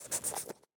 Minecraft Version Minecraft Version snapshot Latest Release | Latest Snapshot snapshot / assets / minecraft / sounds / mob / fox / sniff1.ogg Compare With Compare With Latest Release | Latest Snapshot
sniff1.ogg